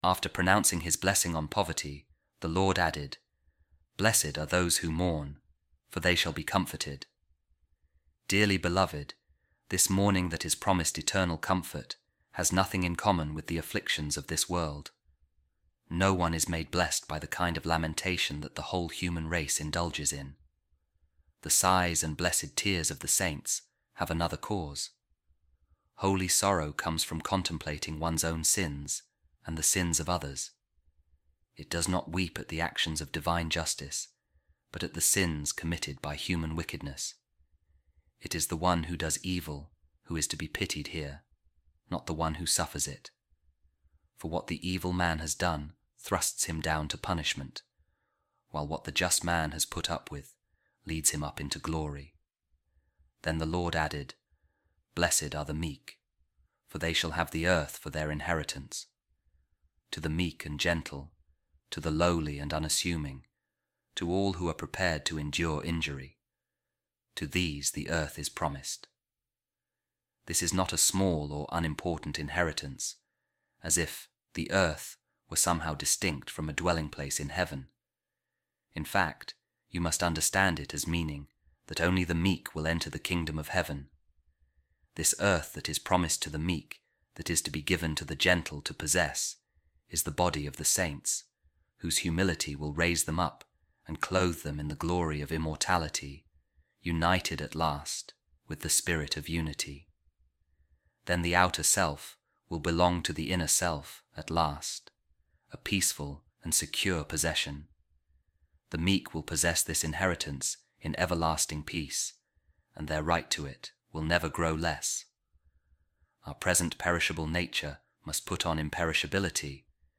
A Reading From The Sermon Of Pope Saint Leo The Great On The Beatitudes | Happiness Of Christ’s Kingdom